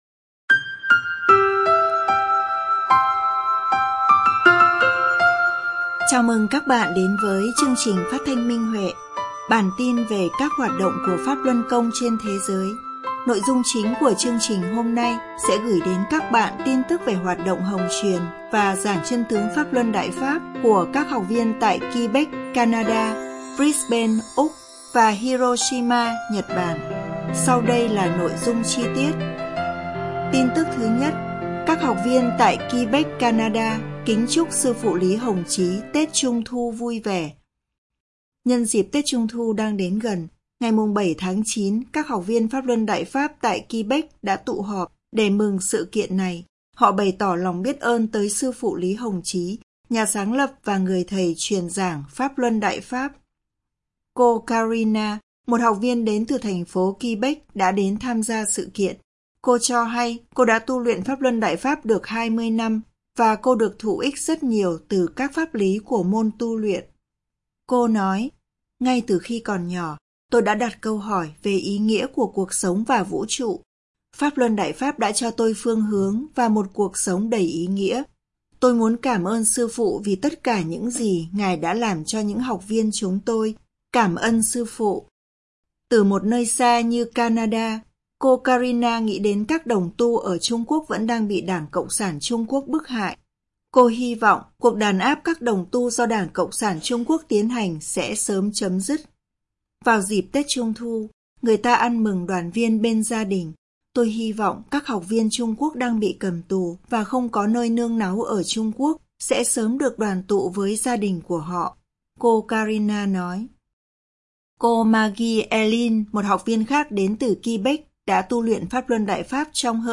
Chương trình phát thanh số 220: Tin tức Pháp Luân Đại Pháp trên thế giới – Ngày 16/9/2024